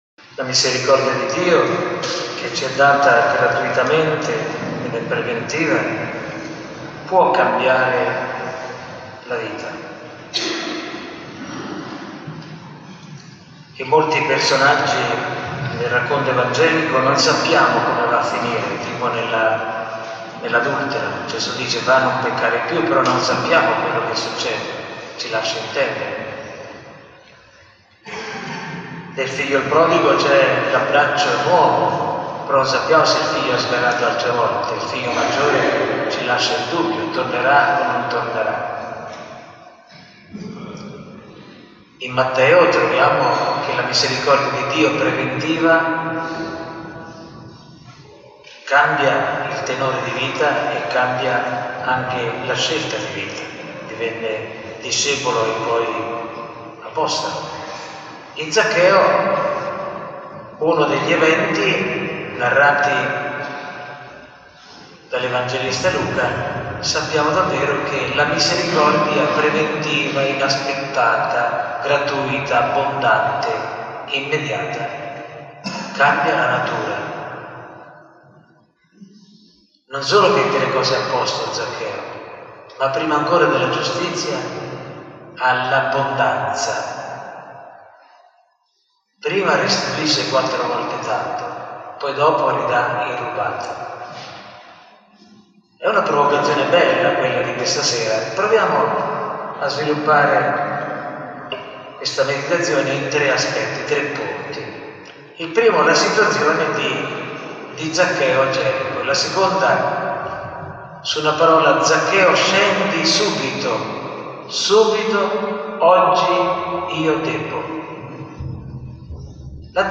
Lunedì 7 marzo si è tenuto il quarto “Lunedì di Quaresima” predicato dal vescovo Armando presso la basilica giubilare di San Paterniano di Fano. Il Vescovo ha meditato il brano di Vangelo di Luca 19,1-10 , di seguito riportiamo il testo guida e l’audio integrale della lectio del Vescovo.